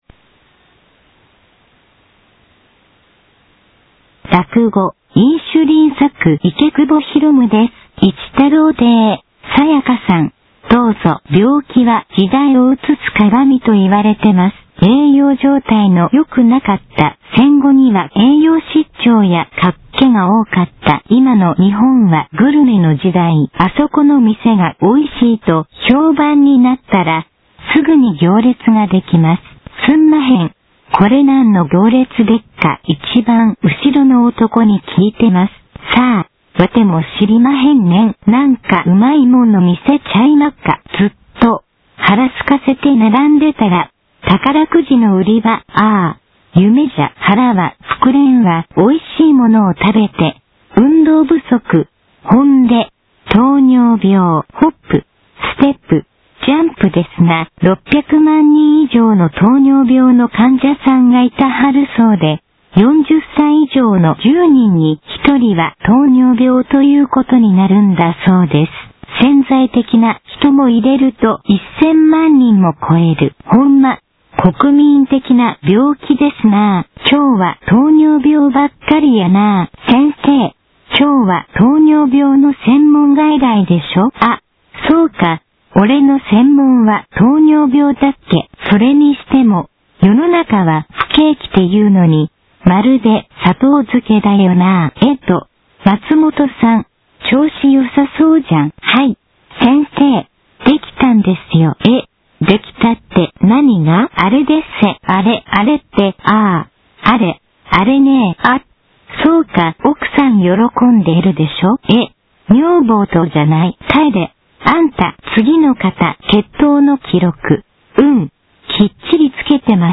これを一太郎の「詠太」で読ませたら……。ヒマだなあ。
ＩＣレコーダーのマイクとパソコンのイヤホンをＡｕｄｉｏ接続コードでつないで、ＩＣレコーダーの録音ＯＮ、すぐに詠太で読ませます。